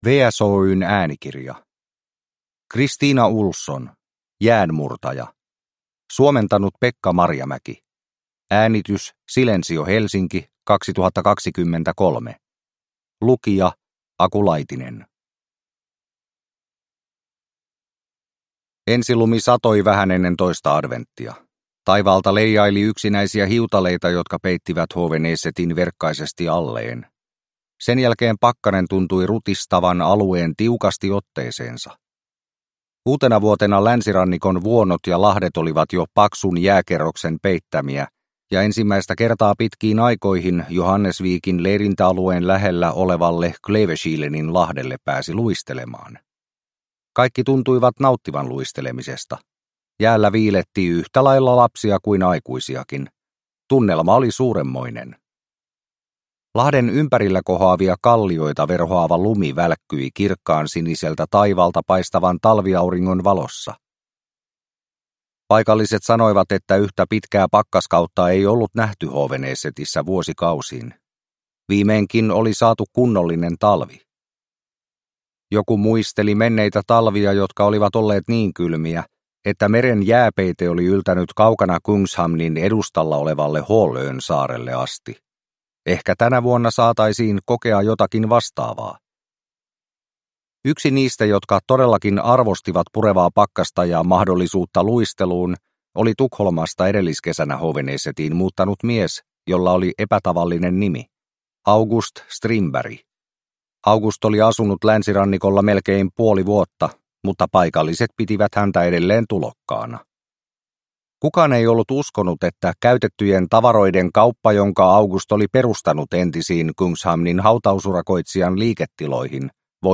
Jäänmurtaja – Ljudbok – Laddas ner